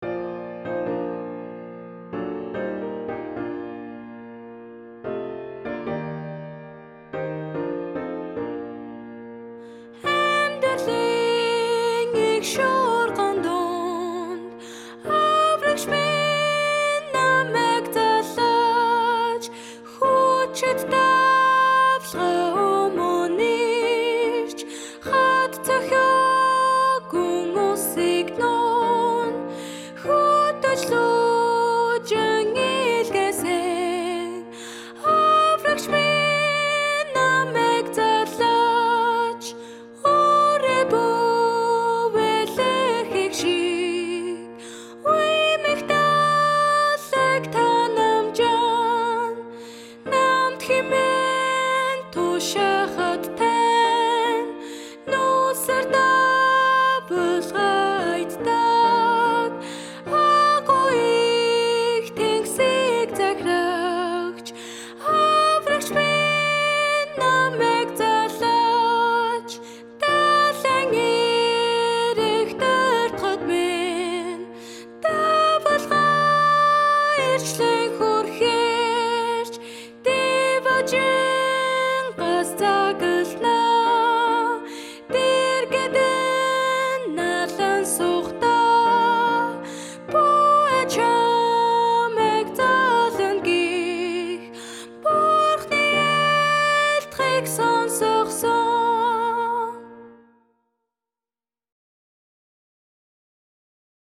Аврагч минь, намайг залаач (MP3 audio (vocal))